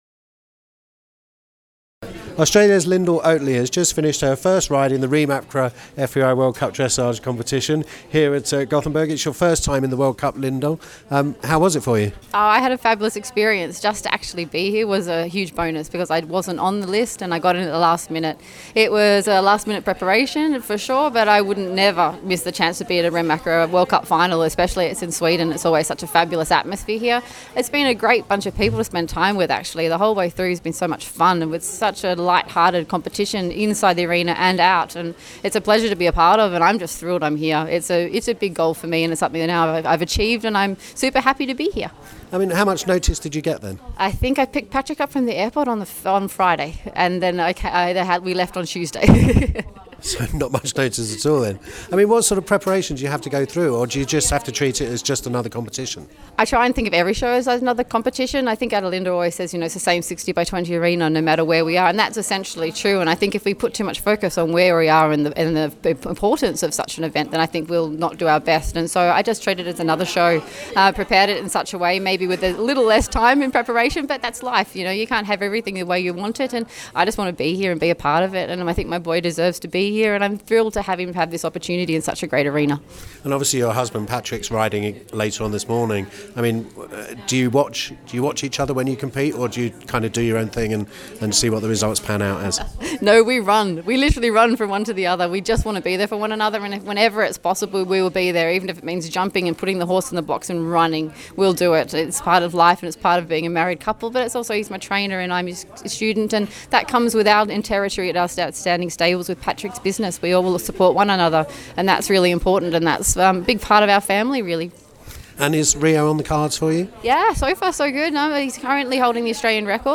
Reem ACRA FEI World Cup Dressage Final 1 - Australia's Lyndal Oatley speaks after her first ride